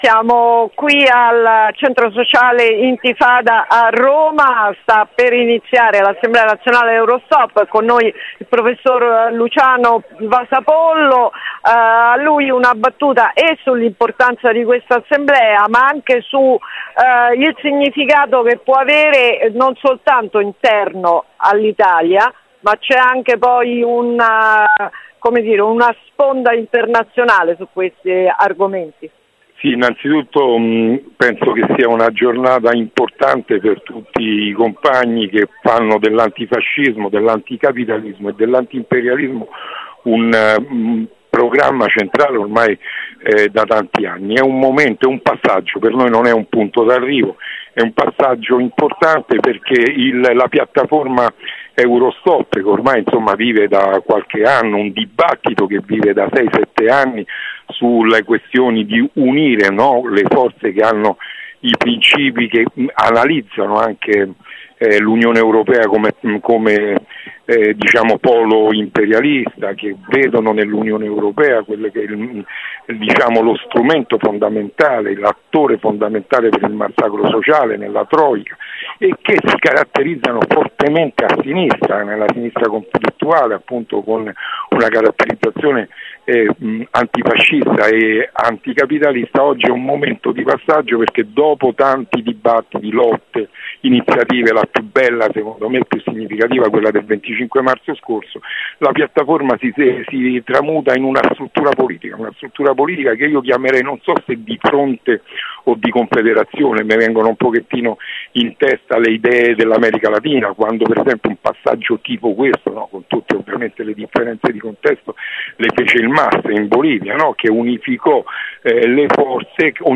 Assemblea Eurostop – Intervista